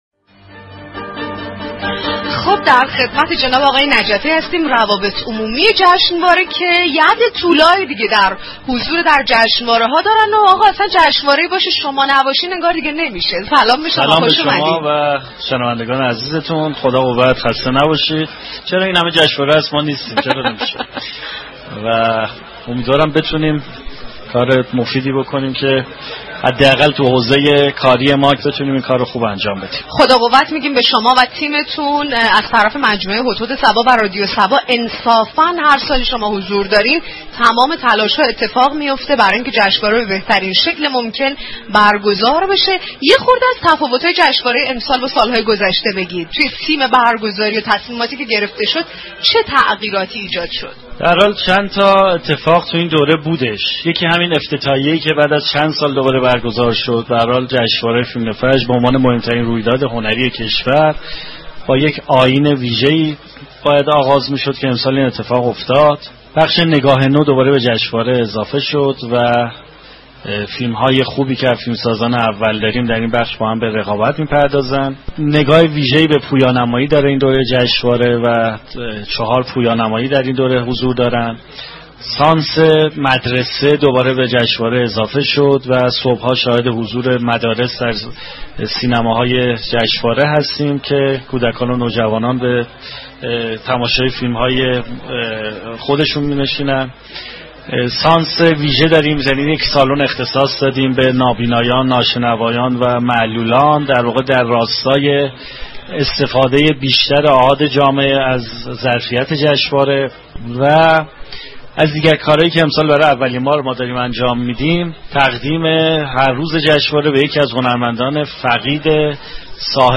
این برنامه با گفتگو با مسولان، هنرمندان، خبرنگاران و ارائه گزارشی از رویداد های این جشنواره و نقد وبررسی اتفاقات جشنواره فیلم فجر از محل برگزاری این جشنواره همراه مخاطبان می شود.